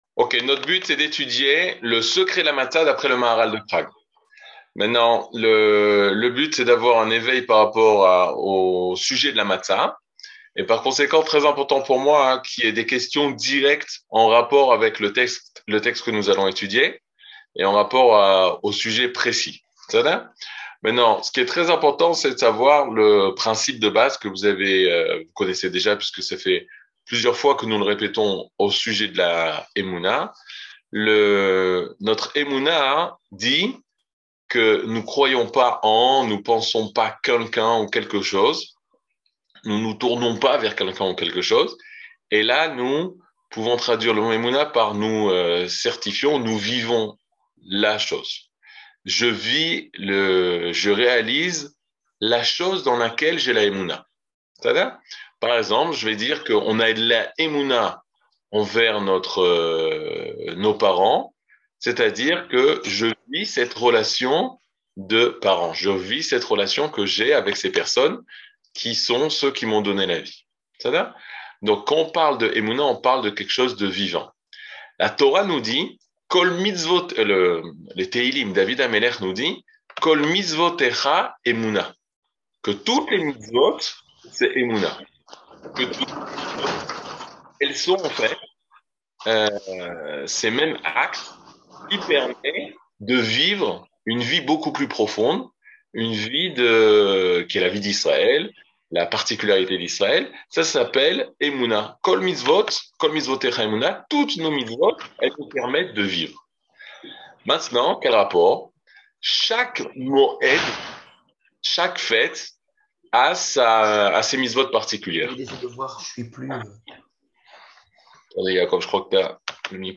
Mini-cours